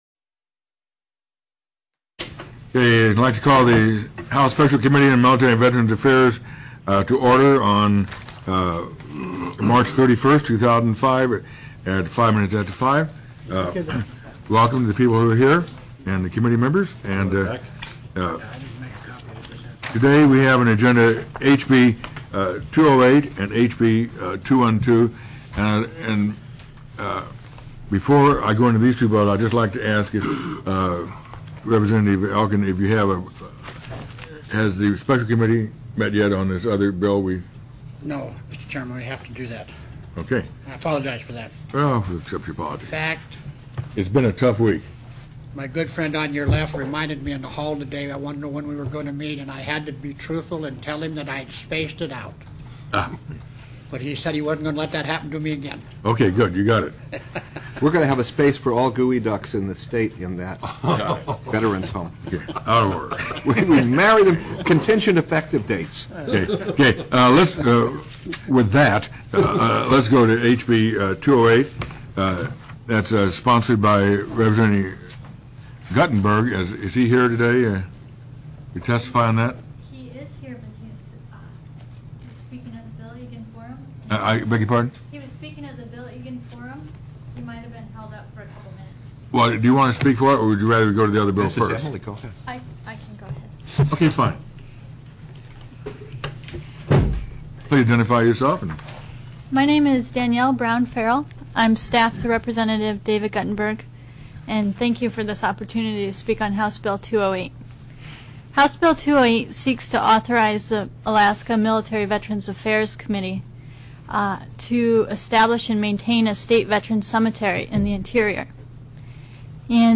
HB 208 STATE VETERANS' CEMETERY TELECONFERENCED